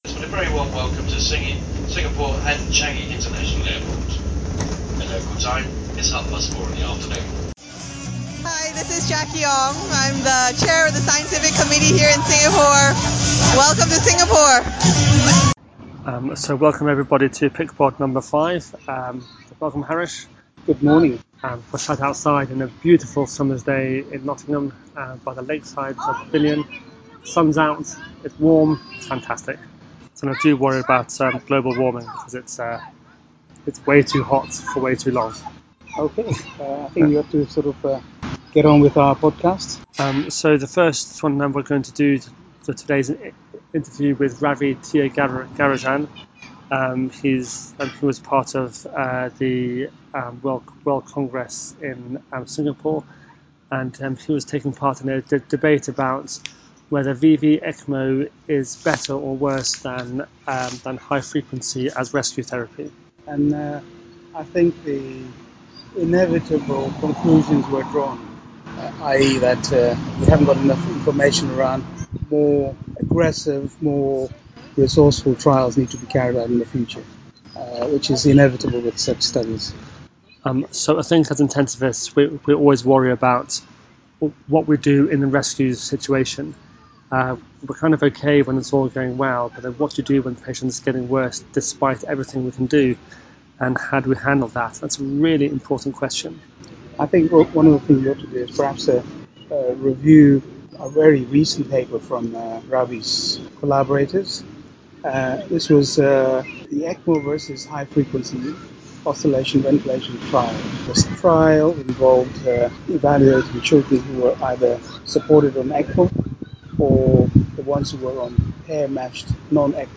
This was recorded after a live debate at the Singapore world congress.